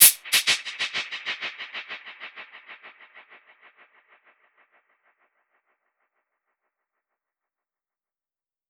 DPFX_PercHit_C_95-02.wav